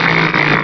Cri de Téraclope dans Pokémon Rubis et Saphir.